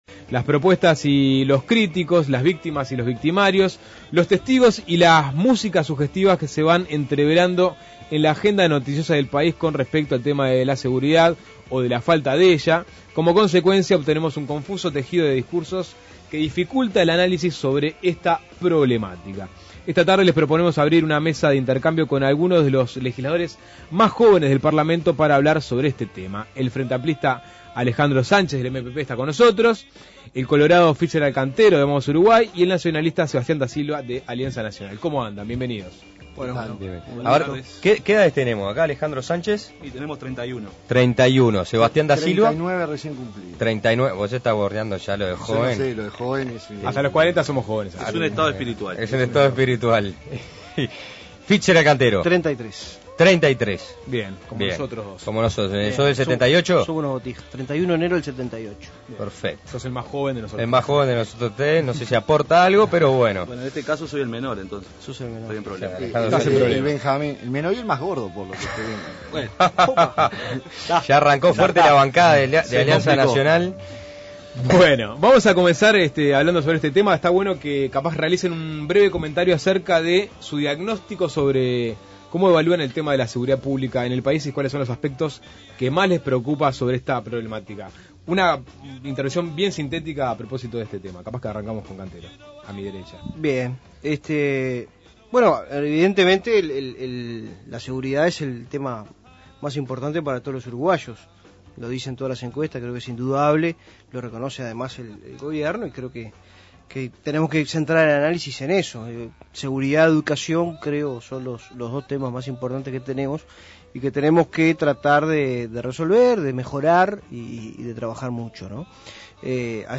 Legisladores jóvenes en mesa redonda por tema inseguridad
Las propuestas, los críticos, las víctimas y los victimarios, los testigos y las músicas sugestivas se van entreverando en la agenda noticiosa del país con respecto al tema de la seguridad, o de la falta de ella. Para aportar elementos sobre este tema, realizamos una mesa de intercambio con algunos de los legisladores más jóvenes del Parlamento: el frenteamplista Alejandro Sánchez (MPP), el colorado Fitzgerald Cantero (Vamos Uruguay) y el nacionalista Sebastián Da Silva (Alianza Nacional).